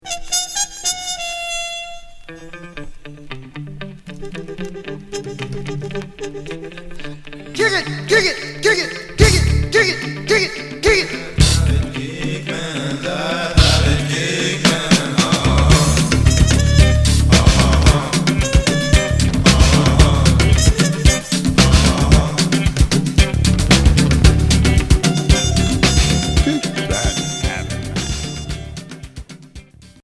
Groove funk expérimental Unique 45t retour à l'accueil